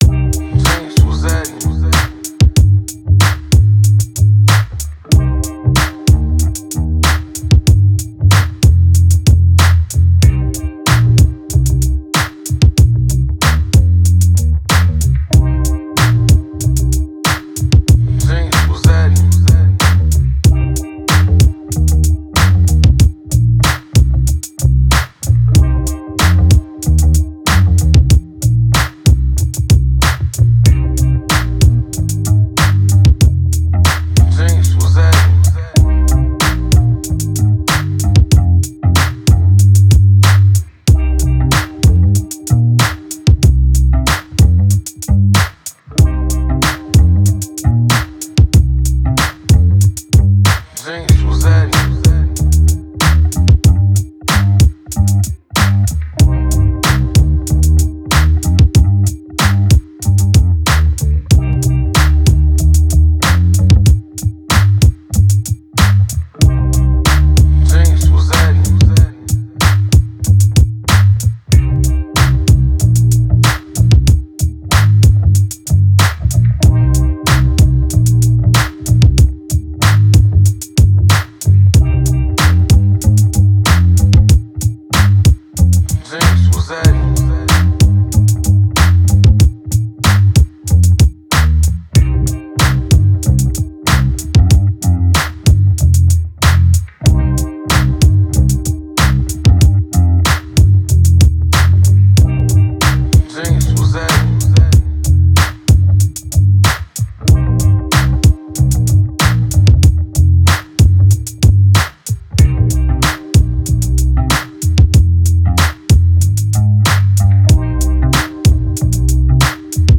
with unique and original guitar bass sounds